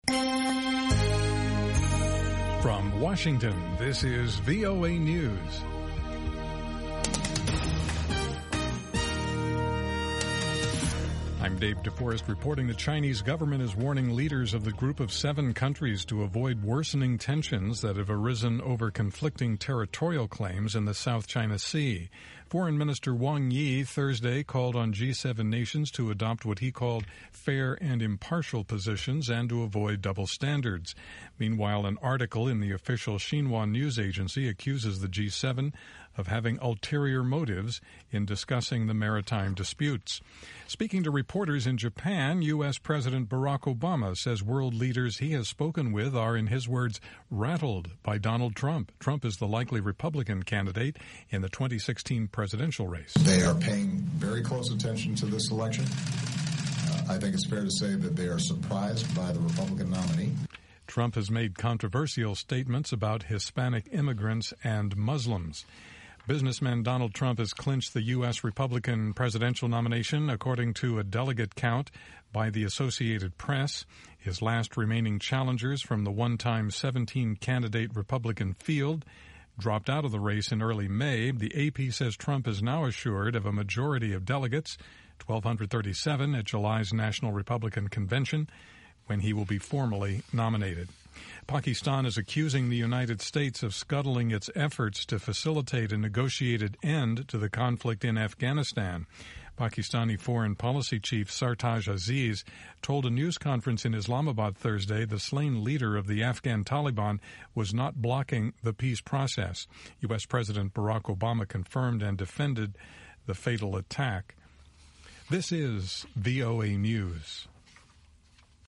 1900 UTC Hourly Newscast in English